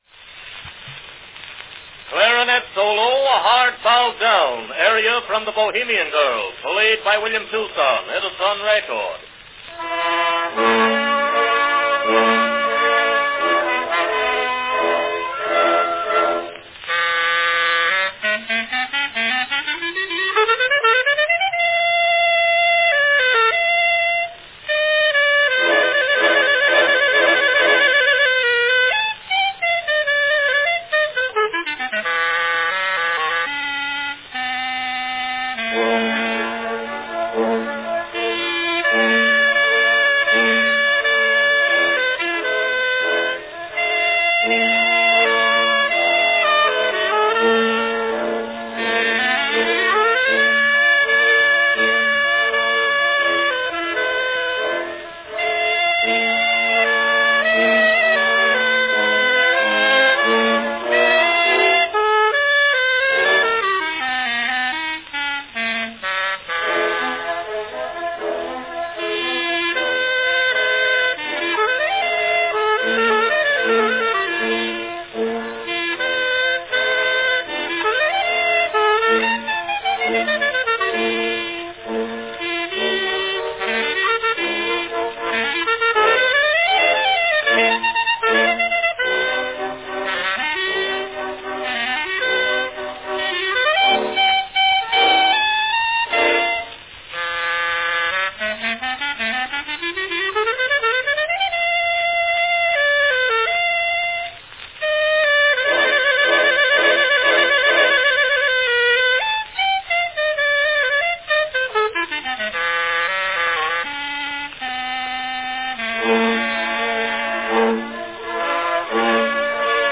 Category Clarinet solo
The clarinet, like the cornet, produced a loud and relatively simple sound that was perfect for recording on the early phonographs.